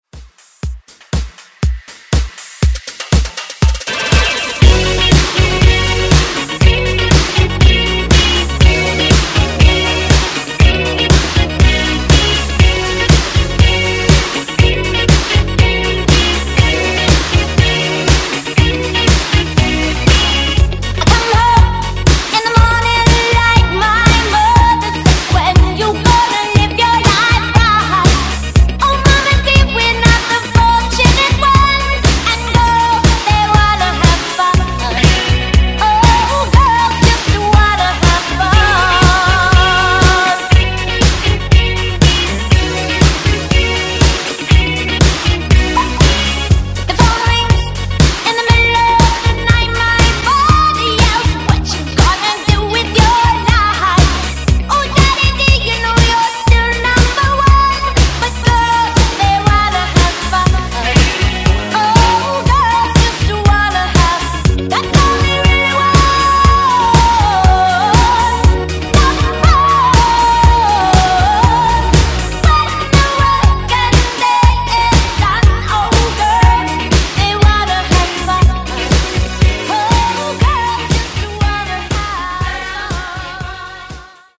BPM: 120 Time